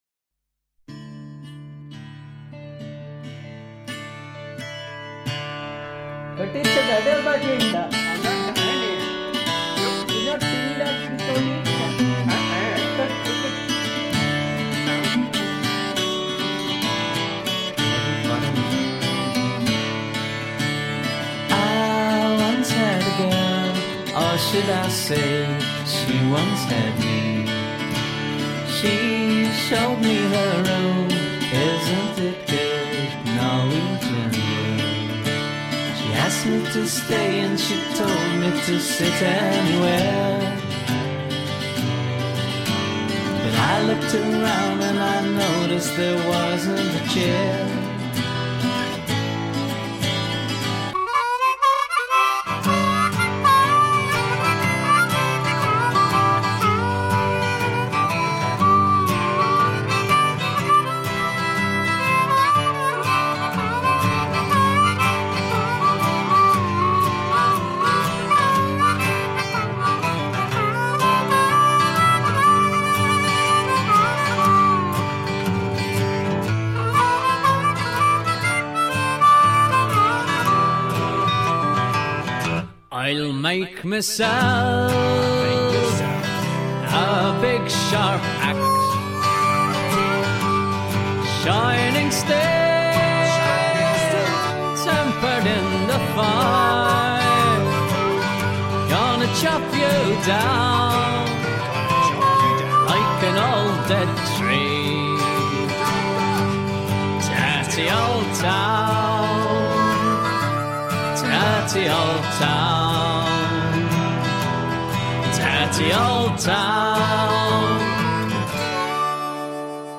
vocals, acoustic guitar, mouth organ
guitars, backing vocals